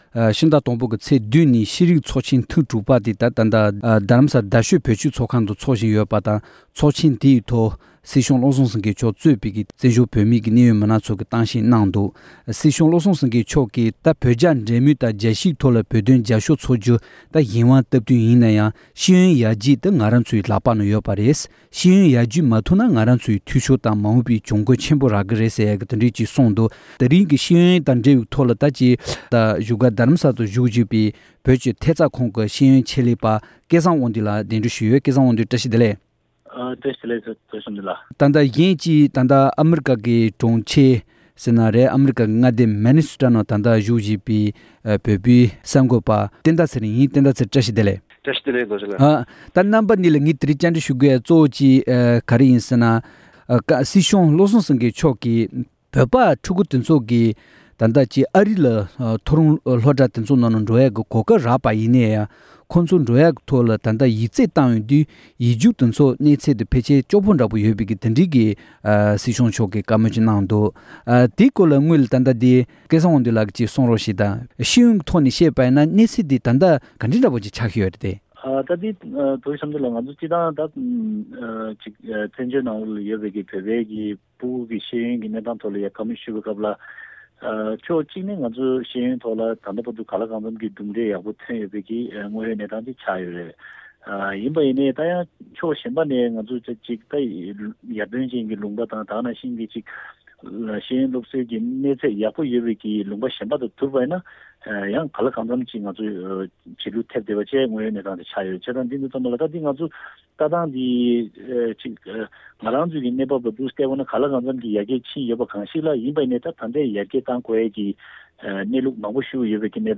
འབྲེལ་ཡོད་མི་སྣ་དང་བཀའ་མོལ་ཞུས་པར་གསན་རོགས༎